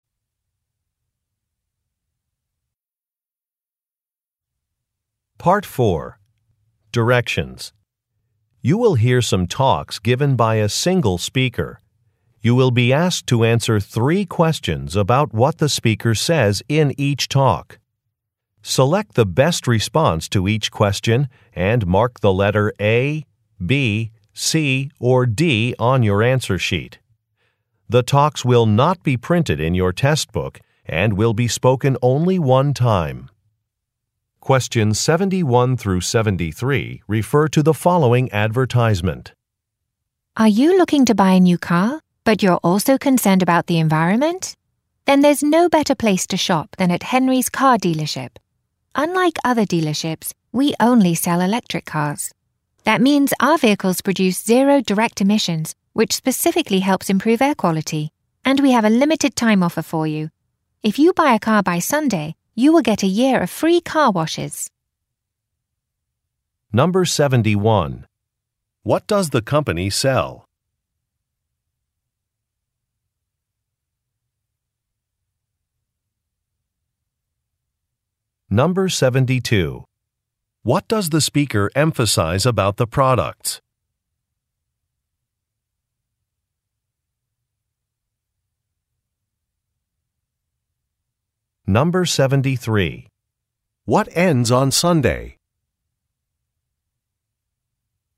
Question 71 - 73 refer to following talk: